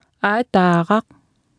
Below you can try out the text-to-speech system Martha.
Speech Synthesis Martha